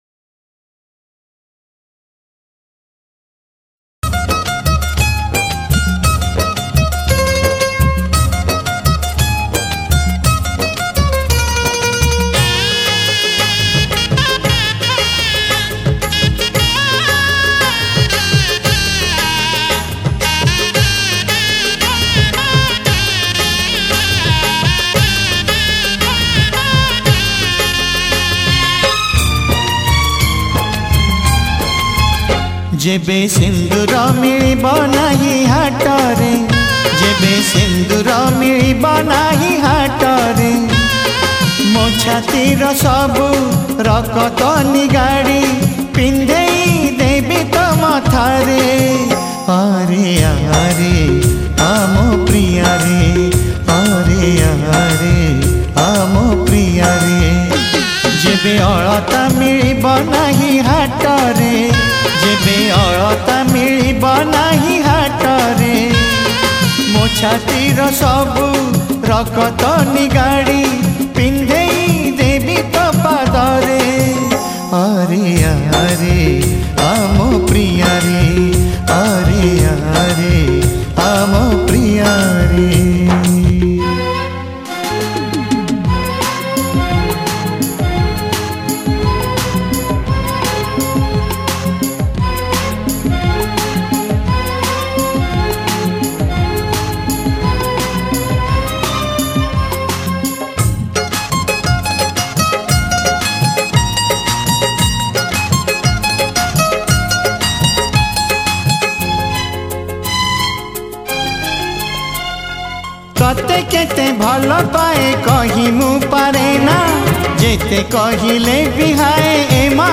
Odia Old Demand Album Sad Songs